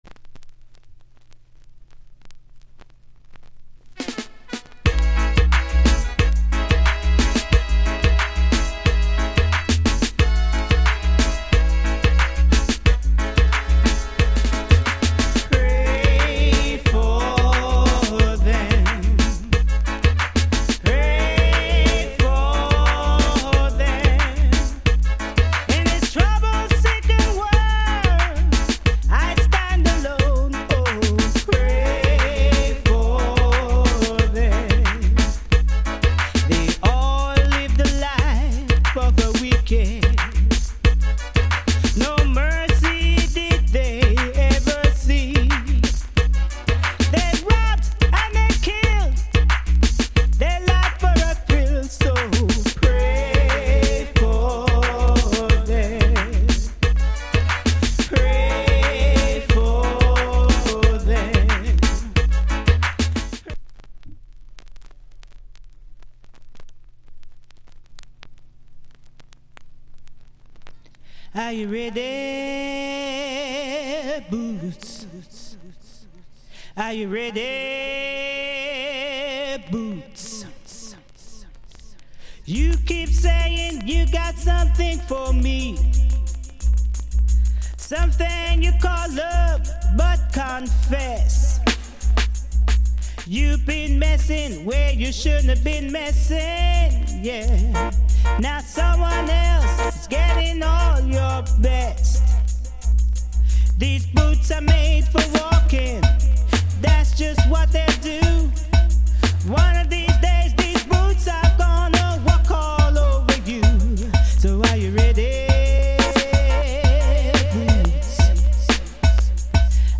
Reggae80sLate / Female Vocal Condition EX Soundclip